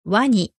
noun | わに
MEANING: alligator; crocodile [wani]